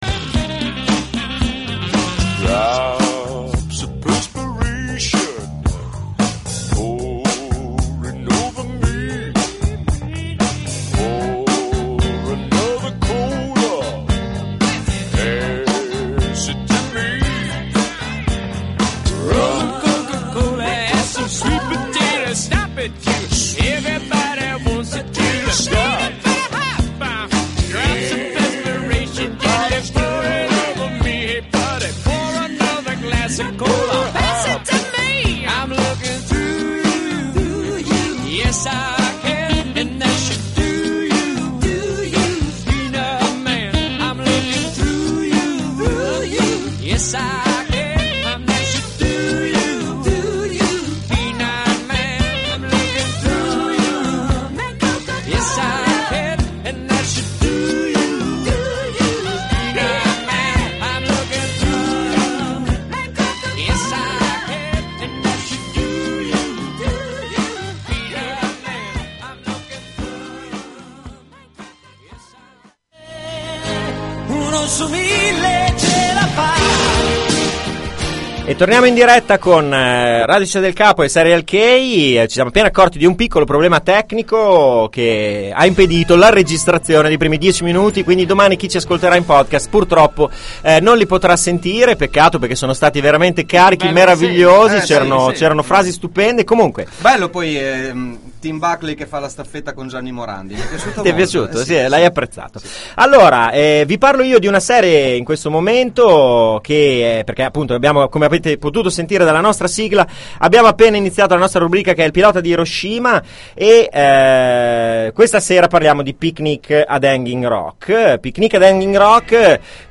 Intanto , tutto per voi, il podcast di “Piano come un Uovo” a cui, purtroppo, mancano i primi 10 minuti, per un errore tecnico…